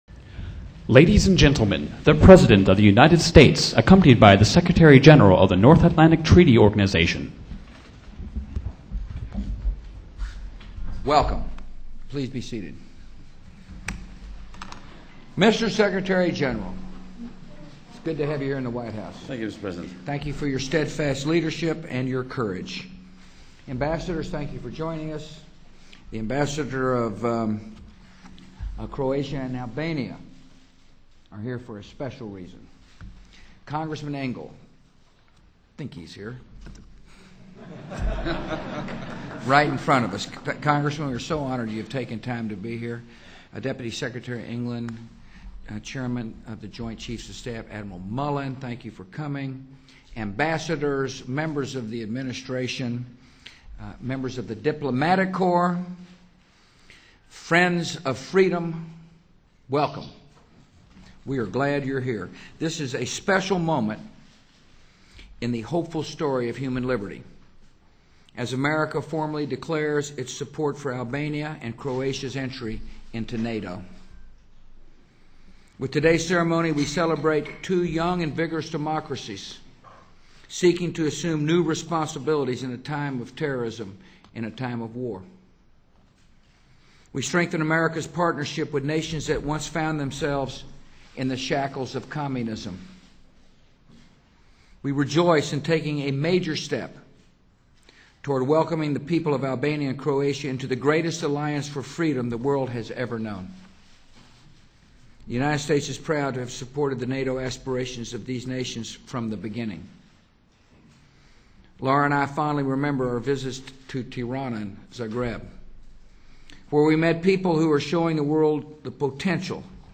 U.S. President George W. Bush speaks at the signing ceremony for NATO Accession Protocols for Albania and Croatia
President George W. Bush speaks with NATO Secretary General de Hoop Scheffer to discuss the NATO Accession Protocols for Albania and Croatia. President Bush recognizes the hardship the two countries have endured to secure their peace and prosperity, believing that their induction to NATO would promise them more stability. Secretary General de Hoop Scheffer calls the accession of Albania and Croatia "a boon for NATO," strengthening the common effort to safeguard and promote security.